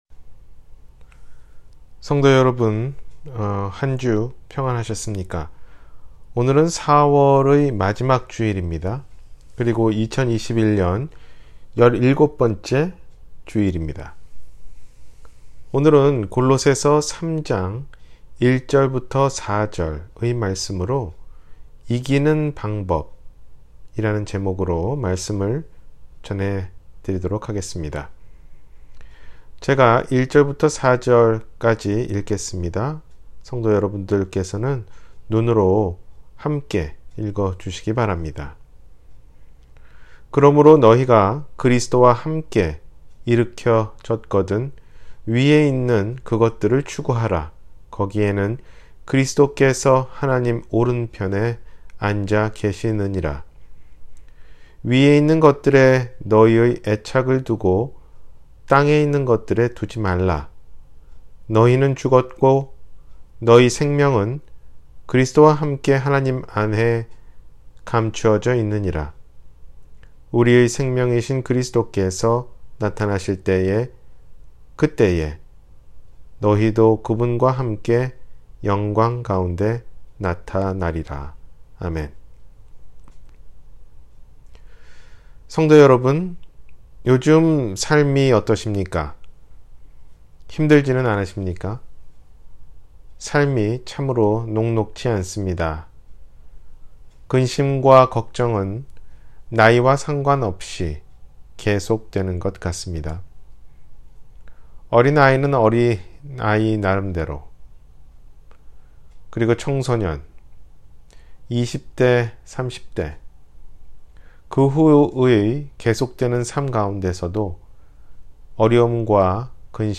이기는 방법 – 주일설교